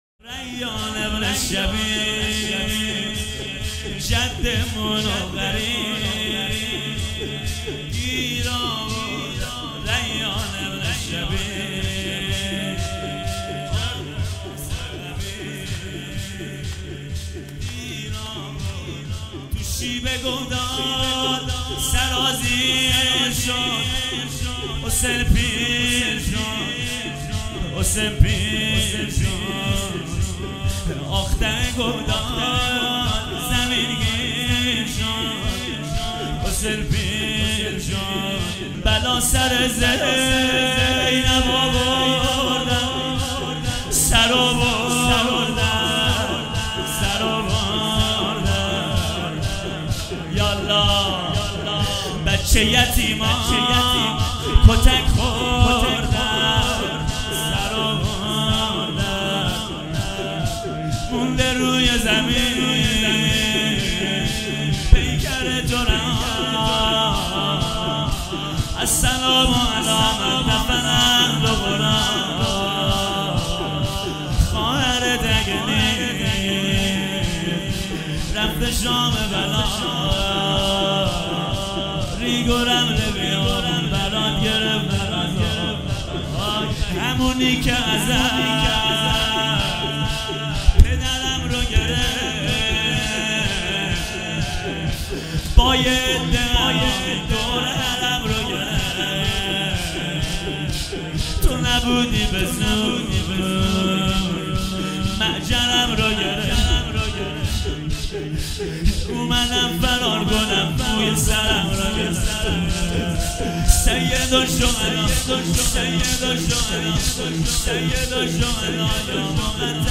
هیئت ام المصائب (س) بابل